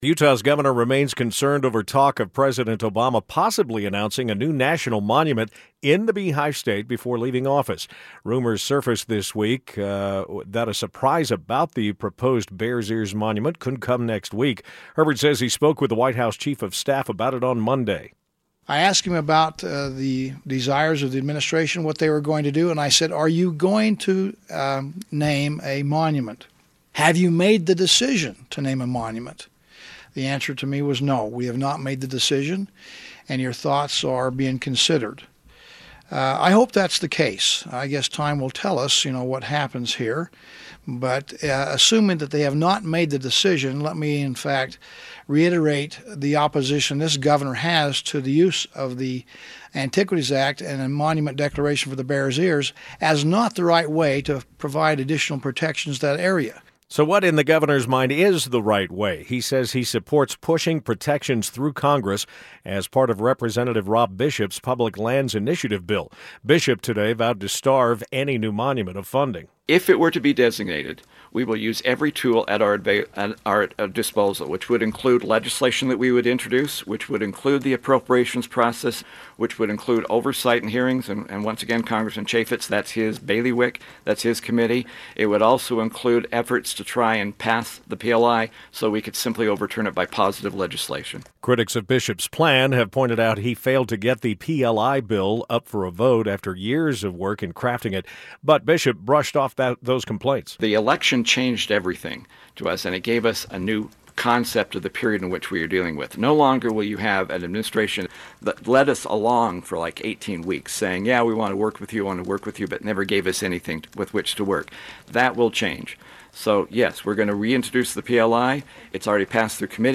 Utah Governor Gary Herbert used his monthly news conference to stage an attack on the possible naming of a new national monument in Utah during the closing days of the Obama administration. Rep. Rob Bishop says he'd defund and undo any new monument, dealing damage to President Obama's public lands legacy.